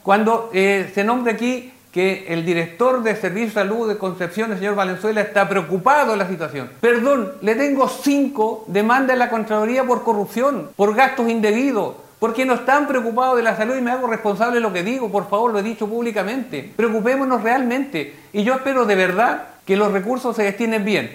En el último programa de El Tablón de Canal 9, los parlamentarios de derecha Flor Weisse y Leónidas Romero, en conjunto con el pre candidato a diputado Alejandro Navarro y la seremi de Gobierno Jacqueline Cárdenas, abordaron la solicitud de dineros de la Subsecretaría de Redes Asistenciales a los GORE.